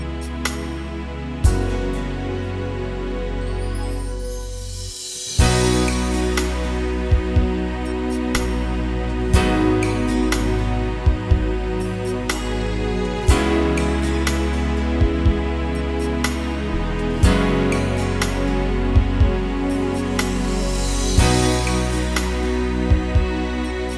Gospel Choir Backing Vocals Only Pop (2000s) 4:09 Buy £1.50